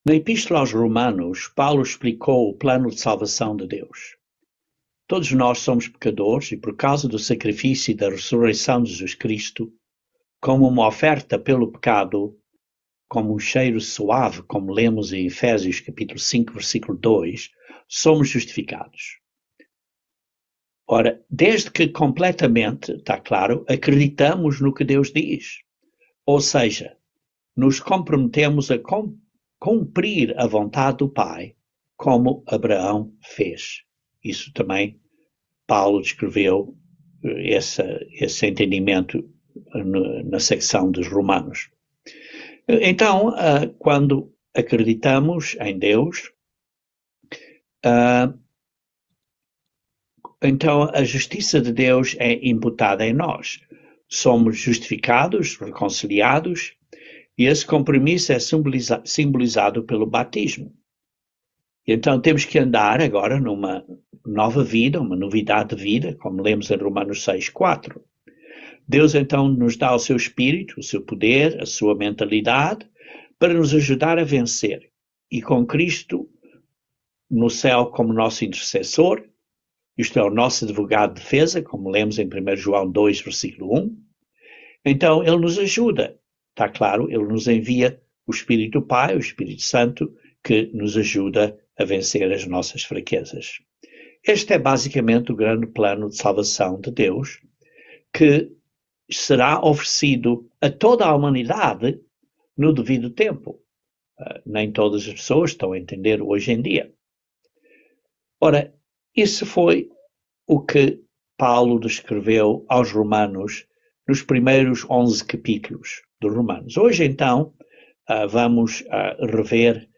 Romanos 12:3-21 - Estudo Bíblico